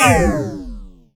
SCIFI_Down_09_mono.wav